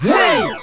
Hey Sound Effect
Download a high-quality hey sound effect.